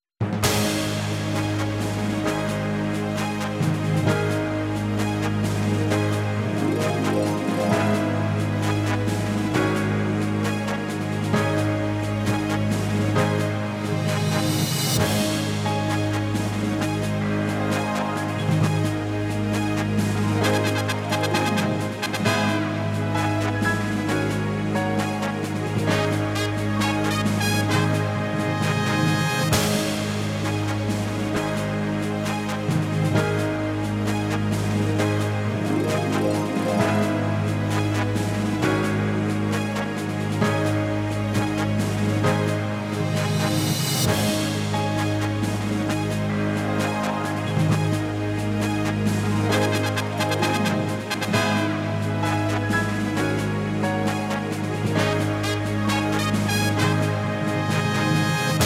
ripped from the Sound Test using Dolphin.